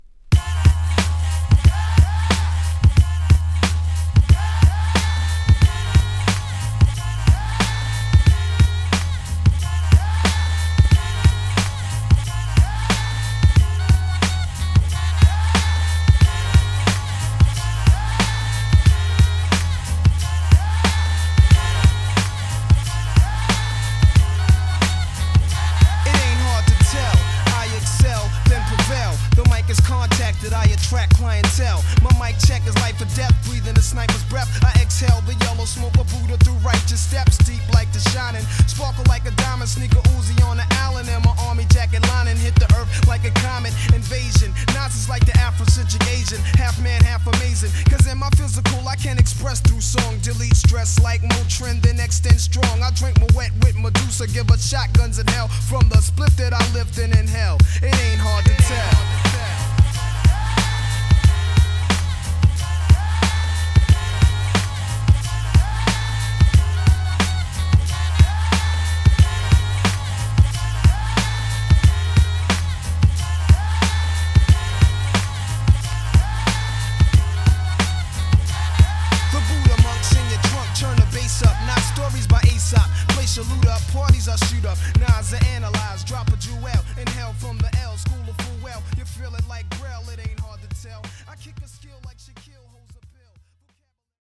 イルなフレイヴァーを塗して仕上げられた トラック。
A-1 MAIN MIX B-1 INSTRUMENTAL B-2 A CAPPELLA